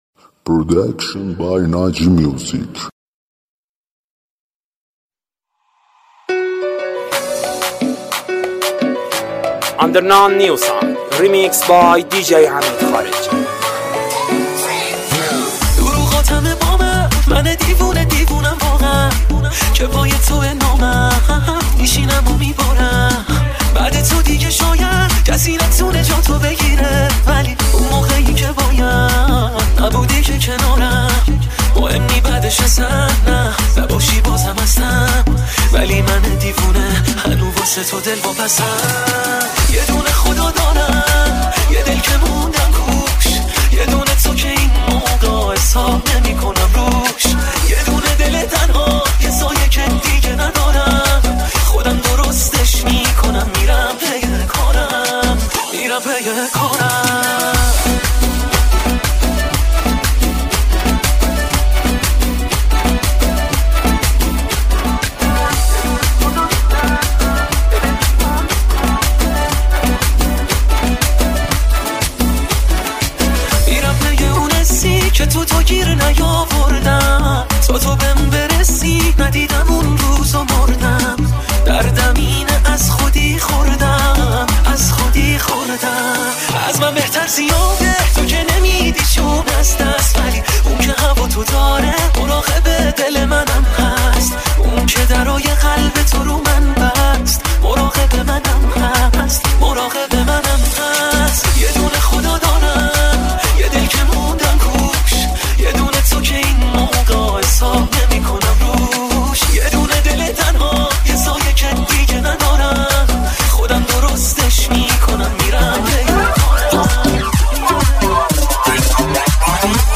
اهنگ شاد ترکیبی برای رقص